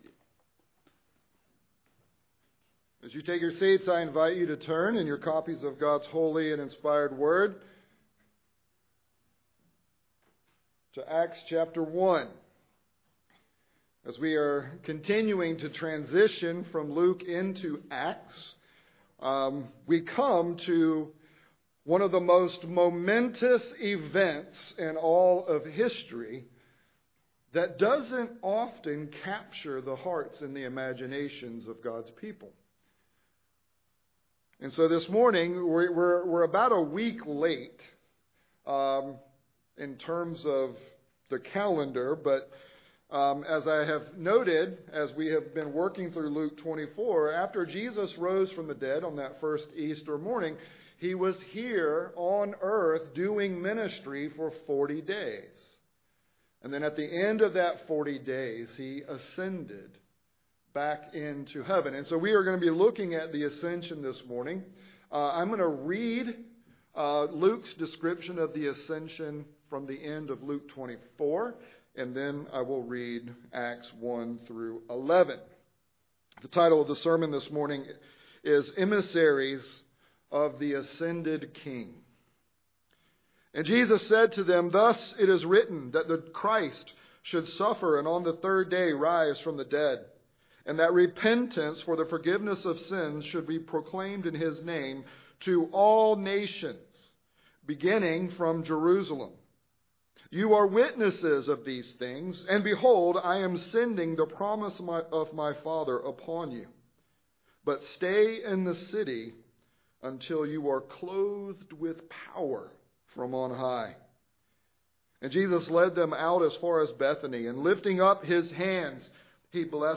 Sermons from Grace Covenant Church: Dallas, GA